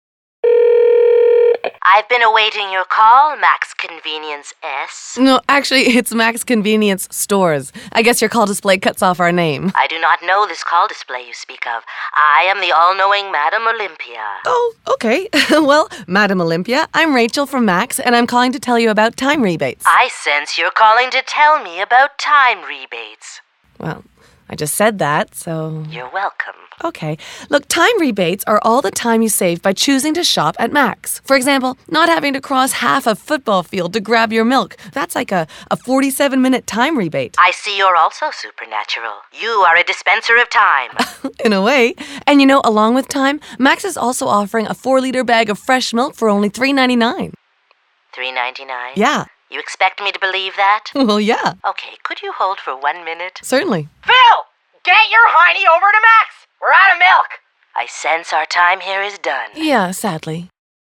Mac's spots recorded at: Pirate Toronto